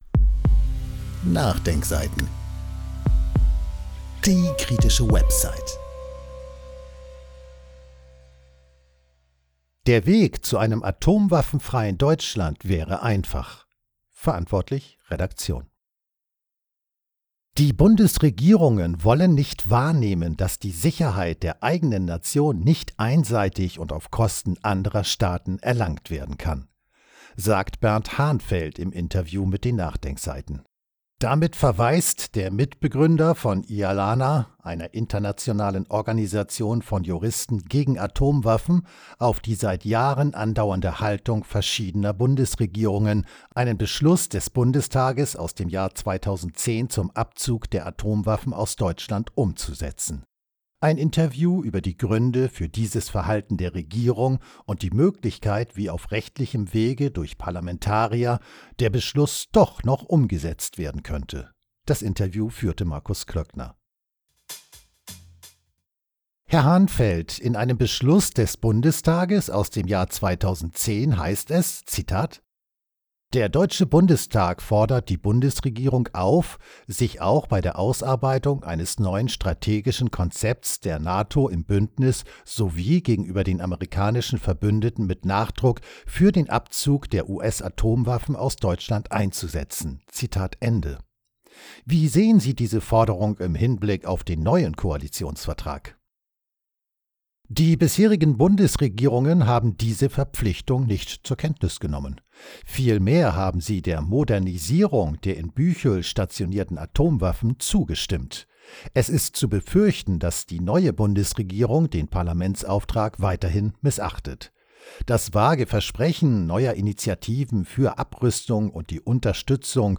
Ein Interview über die Gründe für dieses Verhalten der Regierung und die Möglichkeit, wie auf rechtlichem Wege durch Parlamentarier der Beschluss doch noch umgesetzt werden könnte.